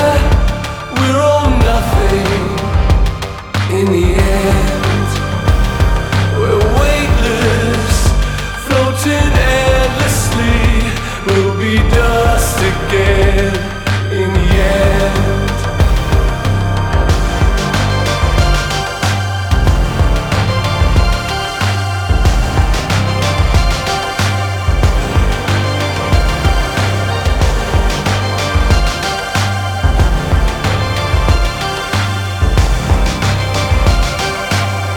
Alternative Electronic
Жанр: Альтернатива / Электроника